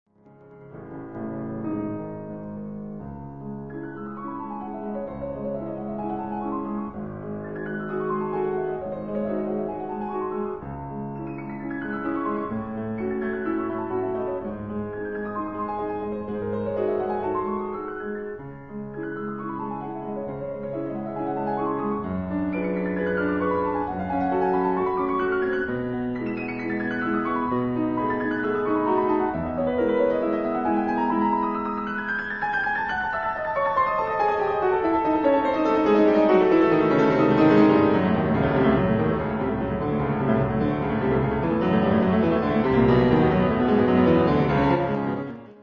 alternant les fortissimo rageurs aux pianissimo d'une grande douceur devant lesquels on ne peut rester insensible.